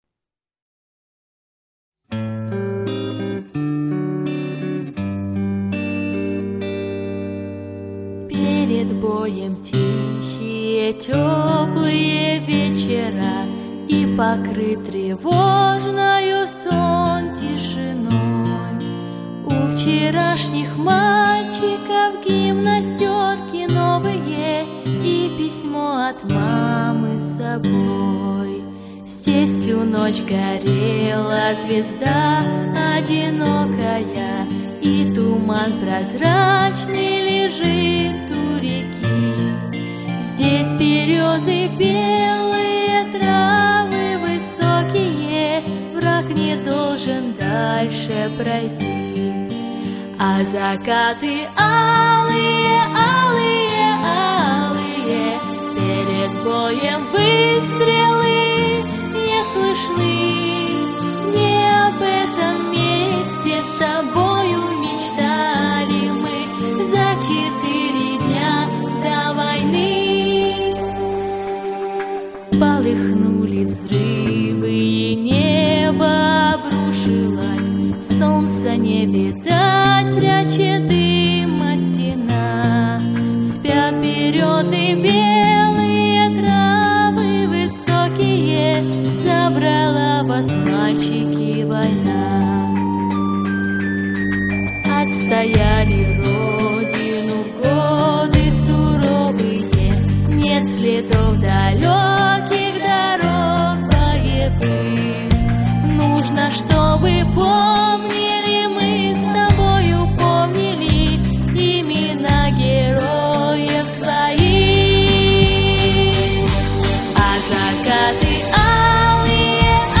очень красиво и душевно!